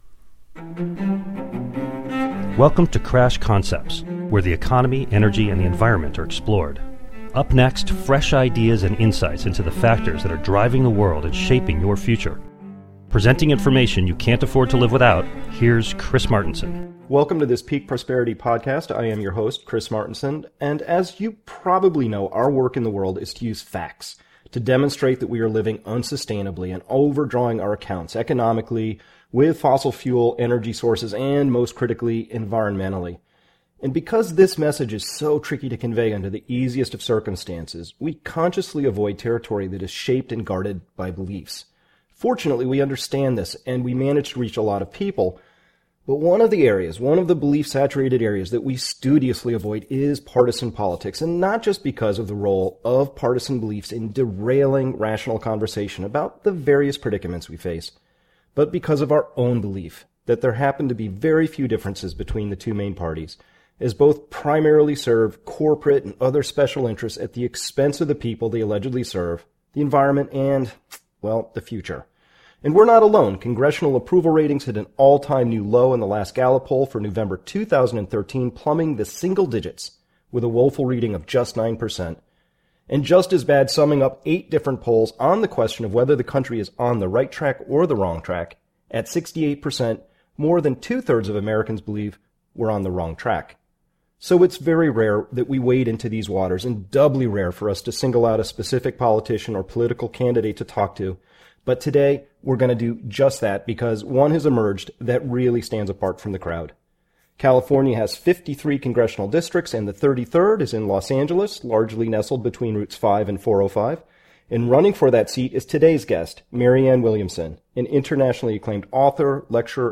This interview addresses key questions like: What power do we have as a populace to effect change? What change should we be demanding?